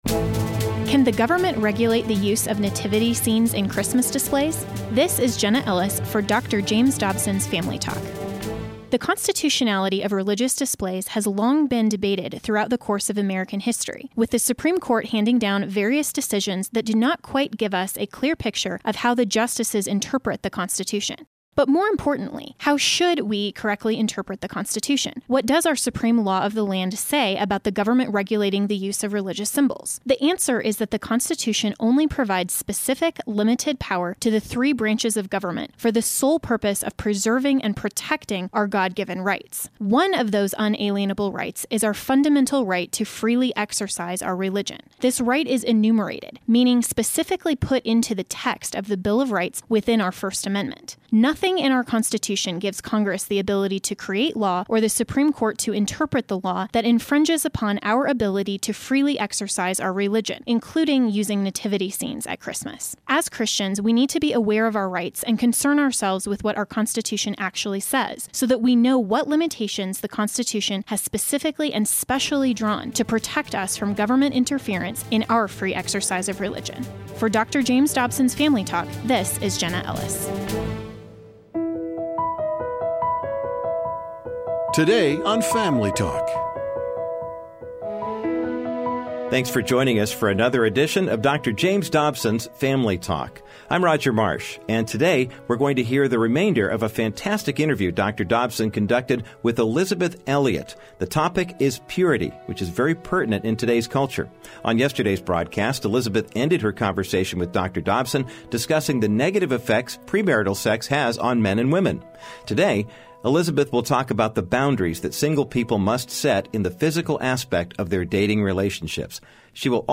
you will hear the remainder of an informative conversation Dr. Dobson had with author and speaker Elisabeth Elliot on the topic of purity. Today they will discuss the temptations single people face and remind those who havent been pure, that forgiveness is always available.